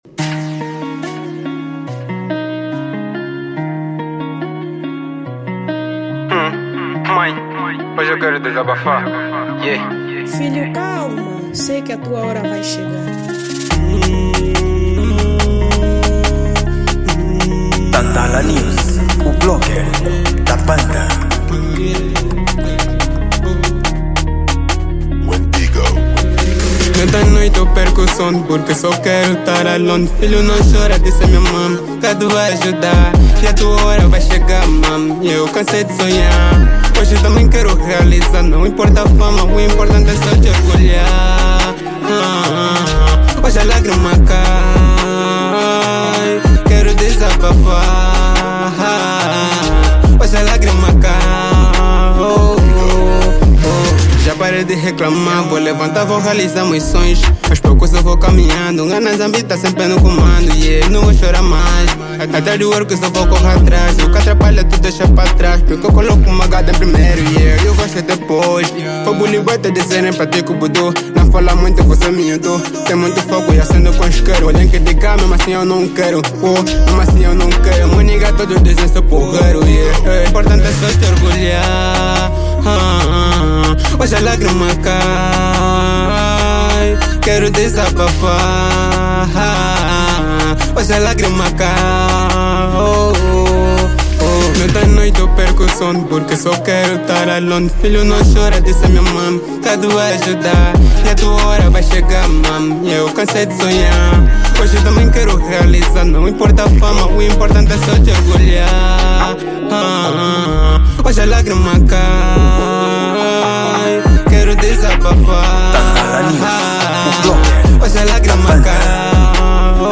Gênero: Drill